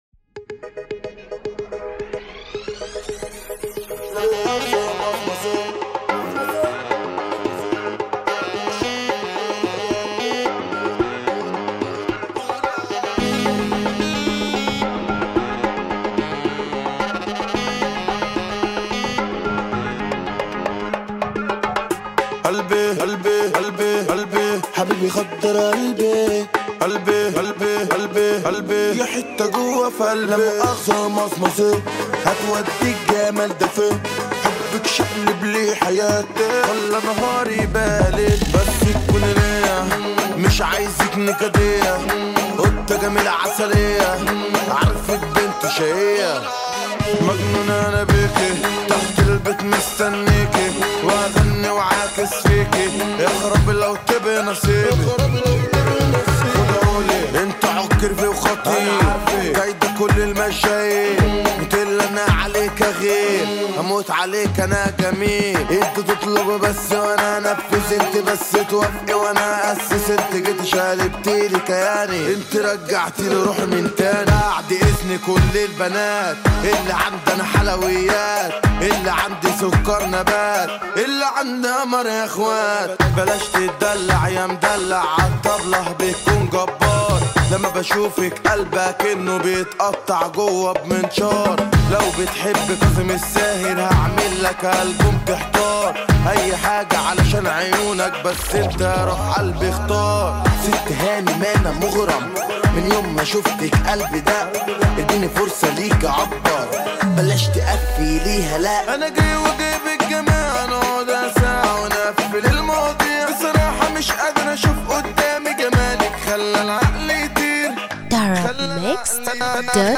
النوع : festival